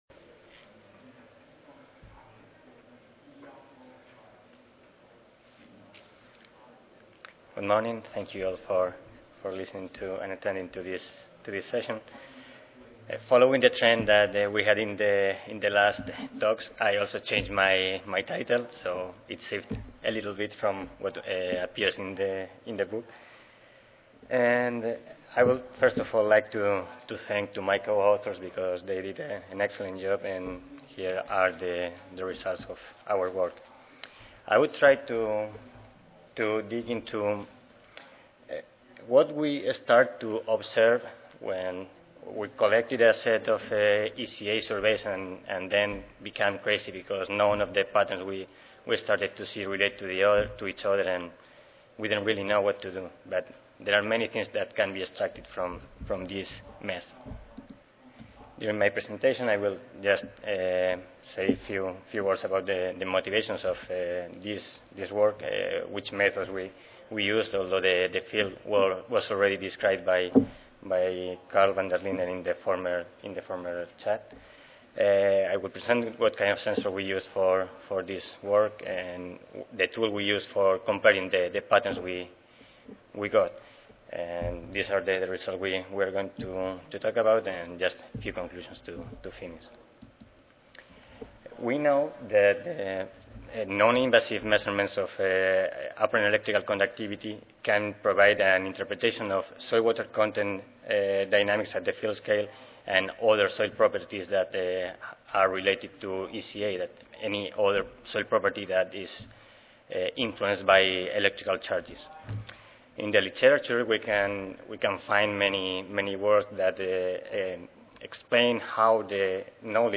USDA-ARS Recorded Presentation Audio File